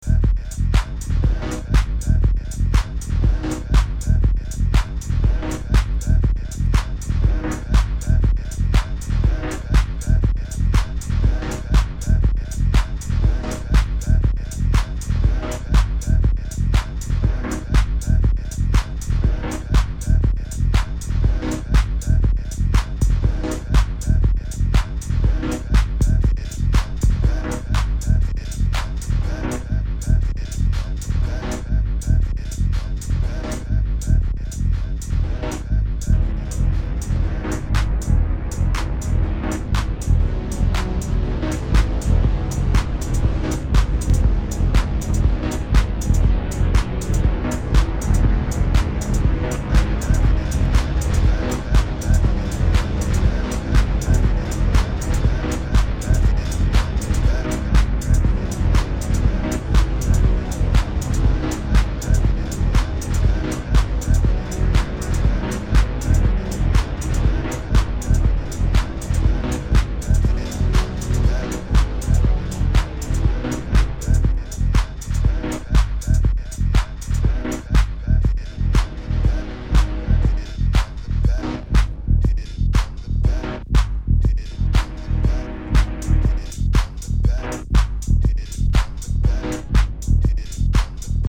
Minimalistic & acidic deep house biz... 150 copies.